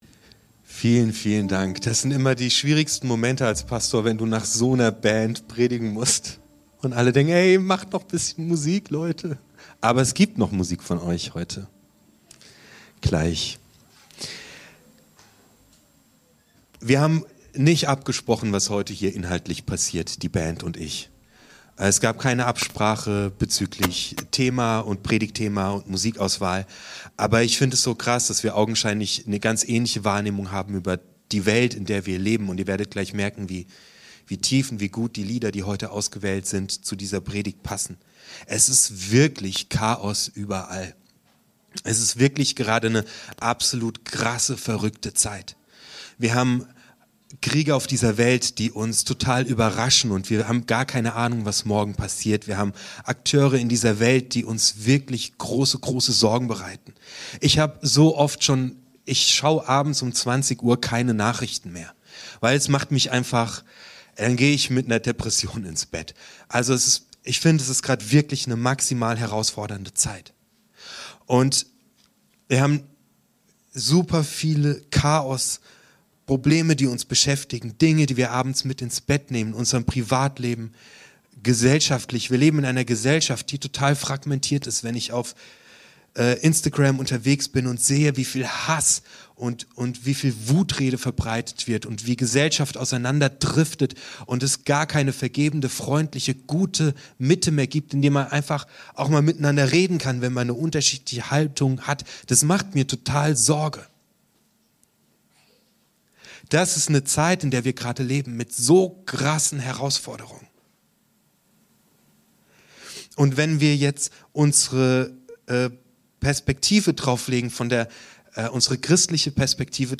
Neuanfang – Aus Chaos wird: In Ordnung ~ Predigt-Podcast von "unterwegs" FeG Mönchengladbach Podcast